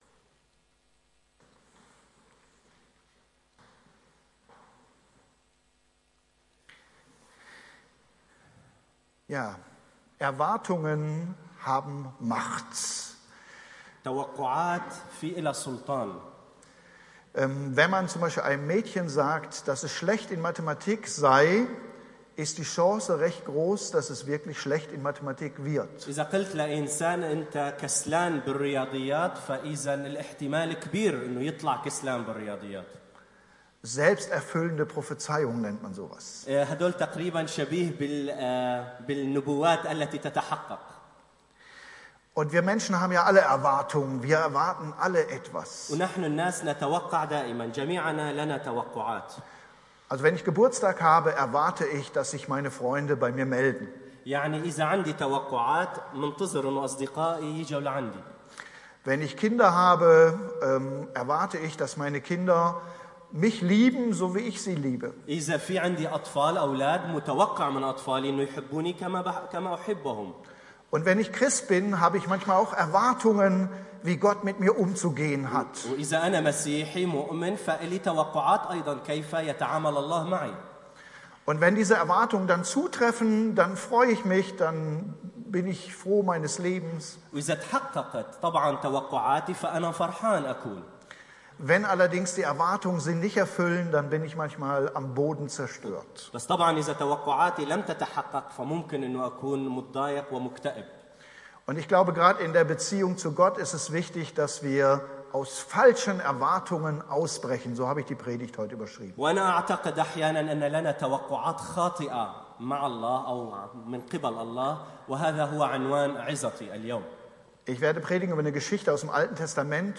Predigt
parallel übersetzt